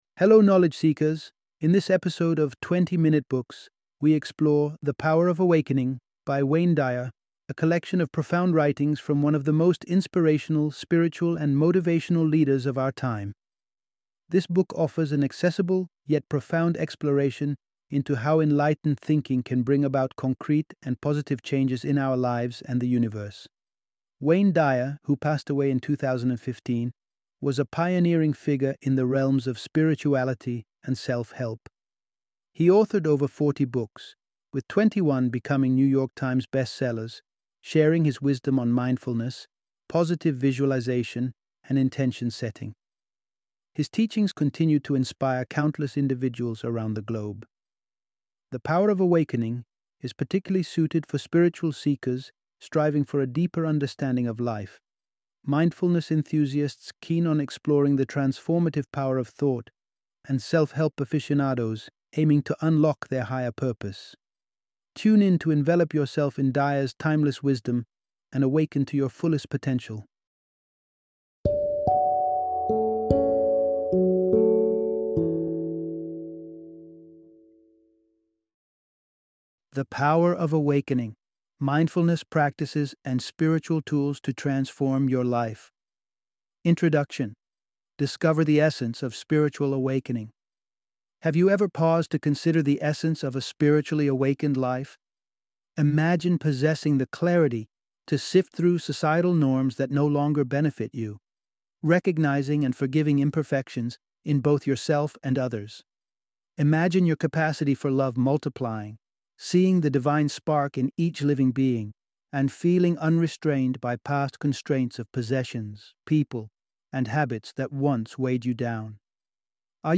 The Power of Awakening - Audiobook Summary